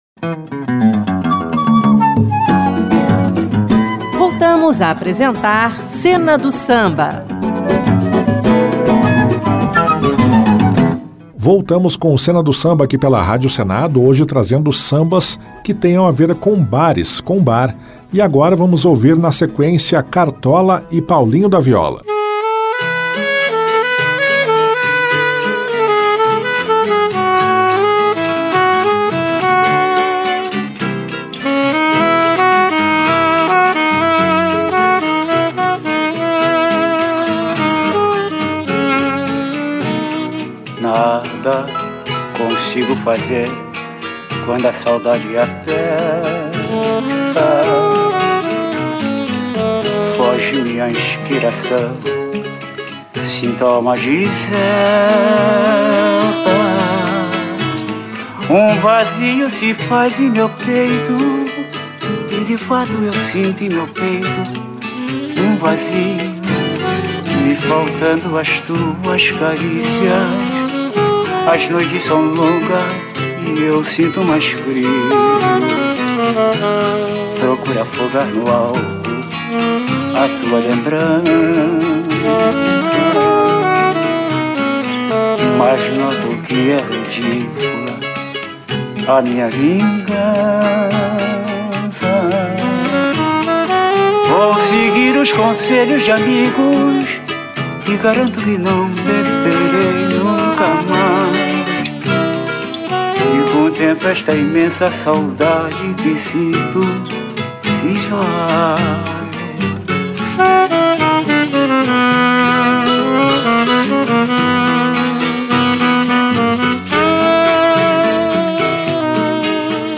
Música Brasileira Samba